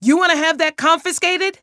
File File history File usage Metadata BullySE-MsPeters_WARNWEAP_v2.ogg  (Ogg Vorbis sound file, length 1.6 s, 106 kbps) This file is an audio rip from a(n) Wii game.